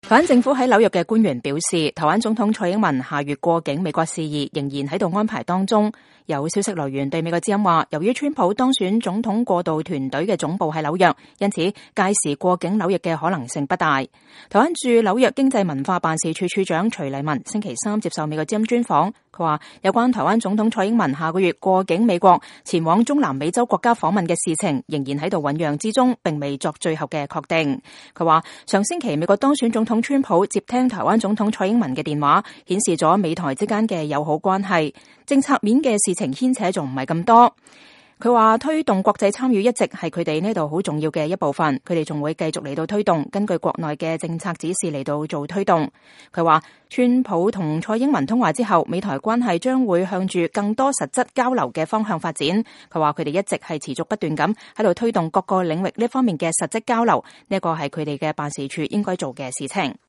台灣駐紐約經濟文化辦事處處長徐儷文星期三接受美國之音專訪。